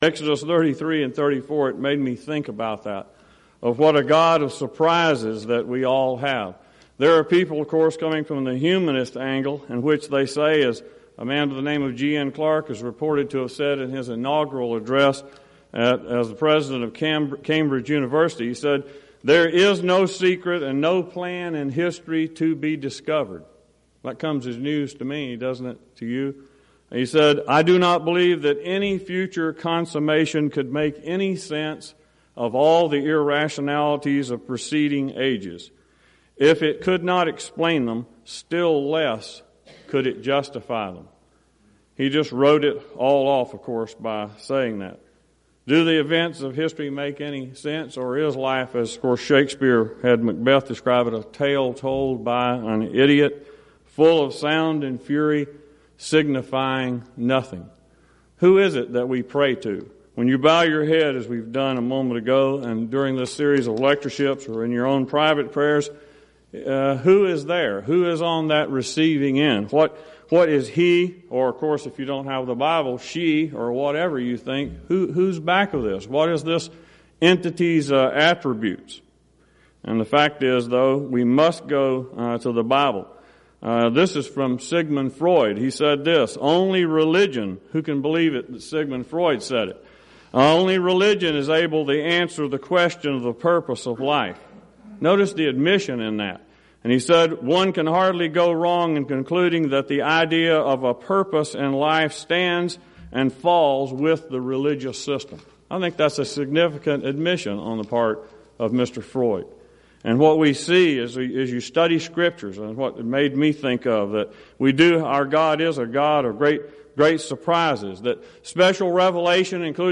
Event: 2nd Annual Schertz Lectures Theme/Title: Studies In Exodus
lecture